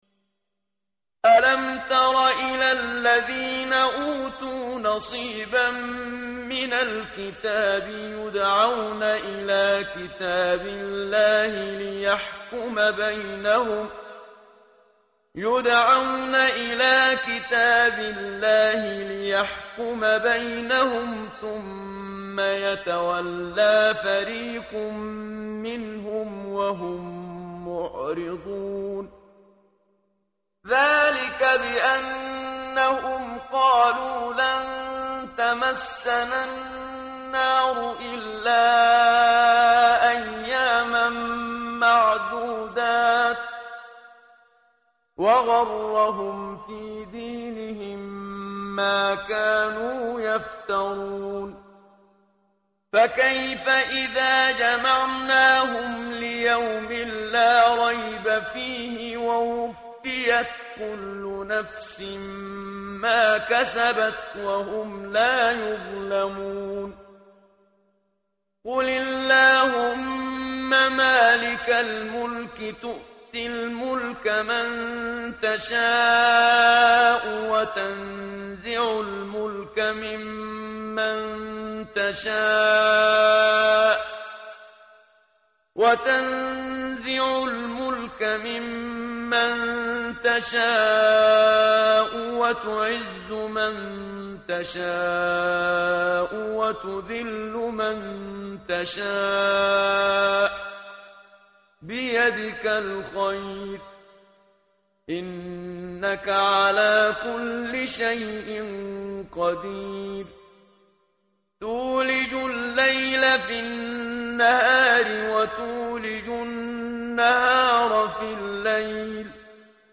متن قرآن همراه باتلاوت قرآن و ترجمه
tartil_menshavi_page_053.mp3